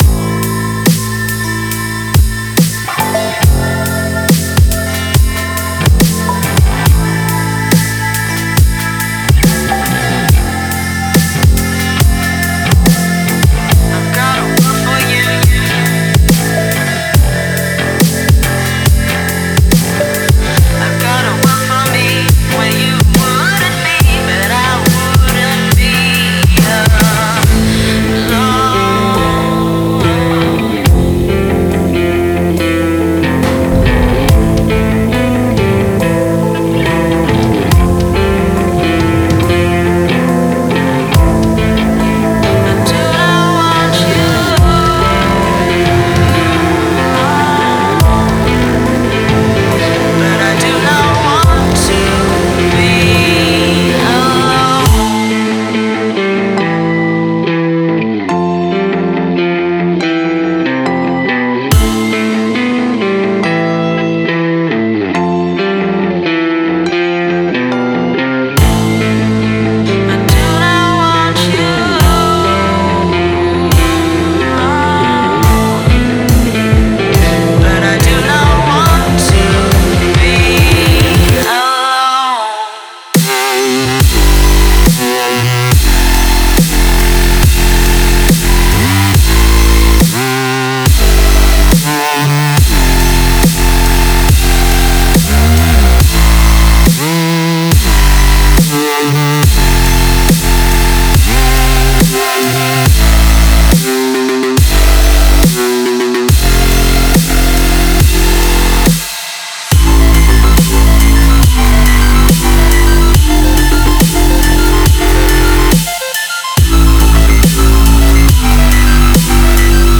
это захватывающая электронная композиция в жанре Future Bass